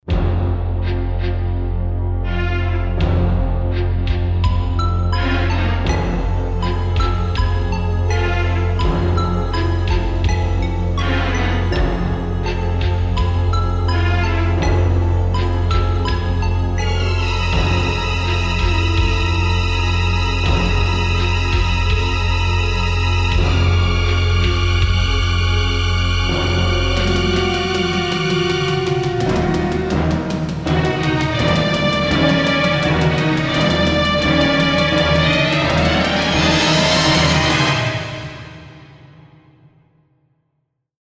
Original Synth Version